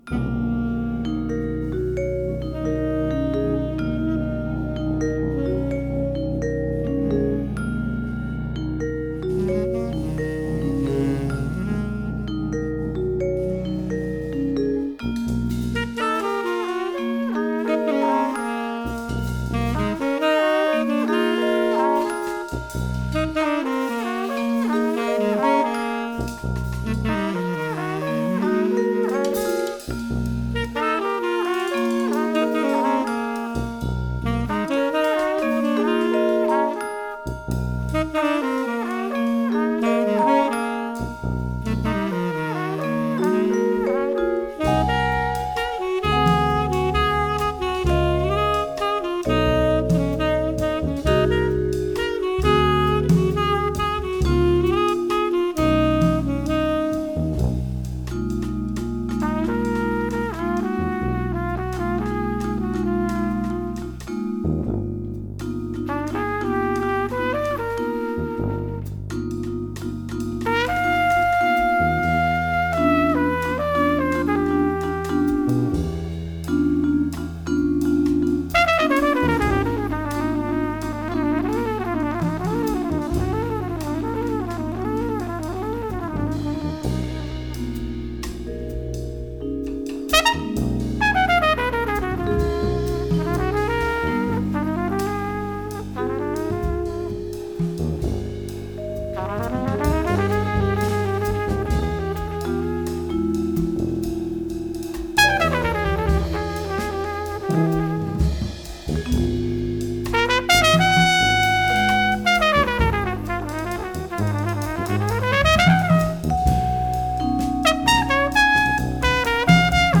saxophoniste
le quintet slovéno-italien